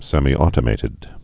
(sĕmētə-mātĭd, sĕmī-)